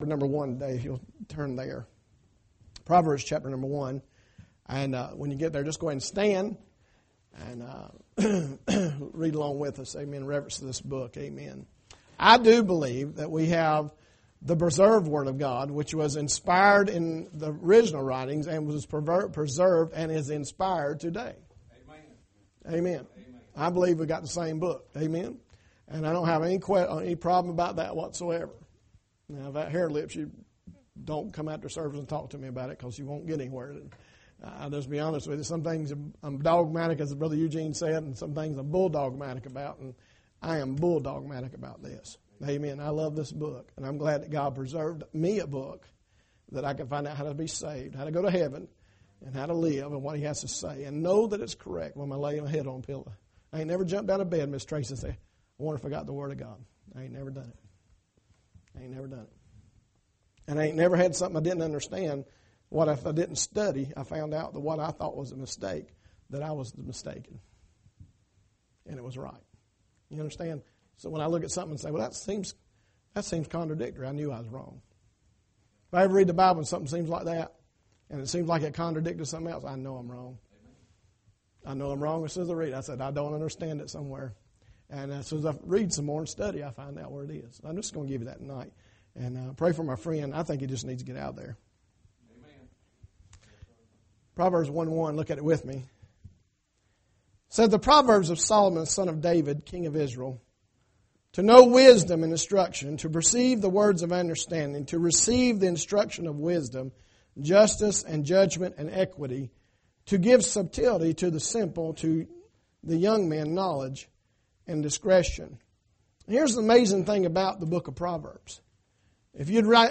Maple Grove Baptist Church, Weddington NC